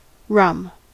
Ääntäminen
Ääntäminen US : IPA : [ˈrəm]